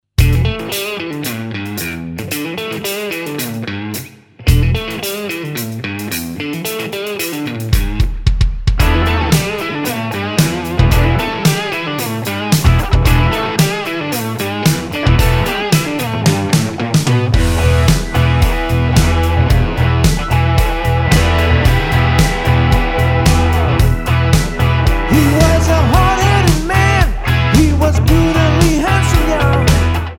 Tonart:E Multifile (kein Sofortdownload.